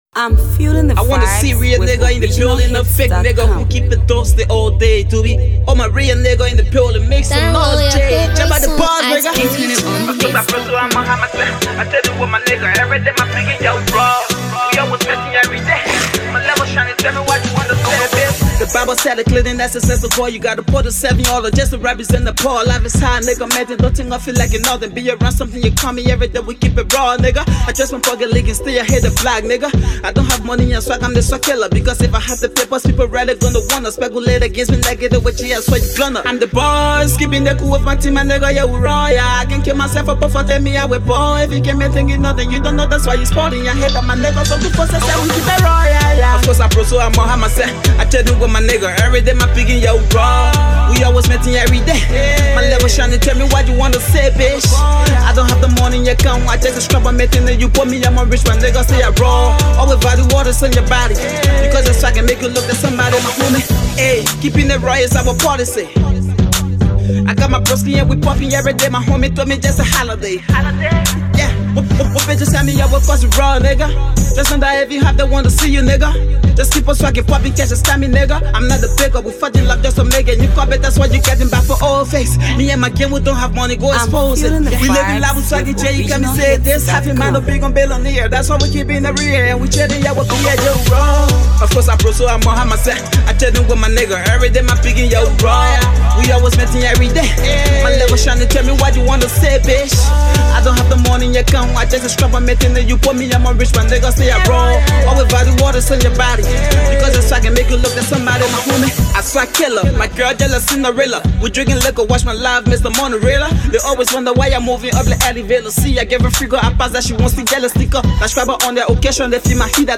AfroAfro PopHipcoMusic
Hipco
hot rap single